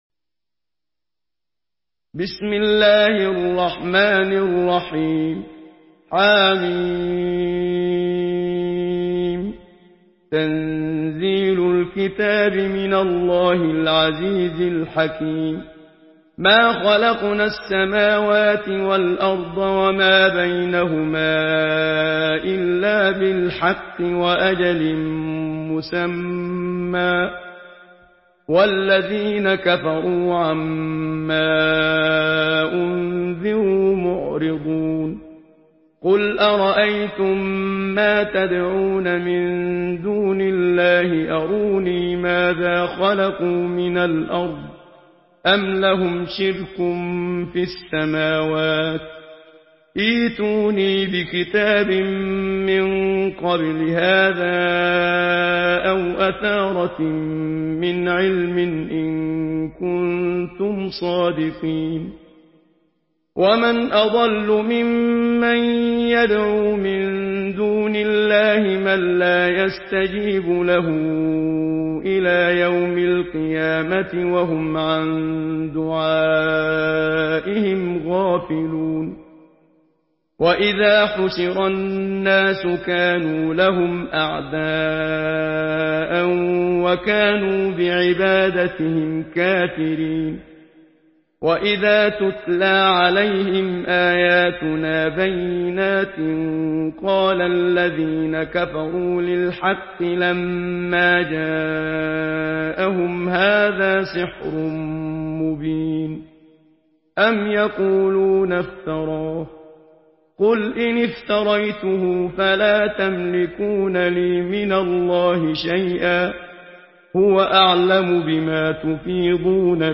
Surah আল-আহক্বাফ MP3 in the Voice of Muhammad Siddiq Minshawi in Hafs Narration
Murattal Hafs An Asim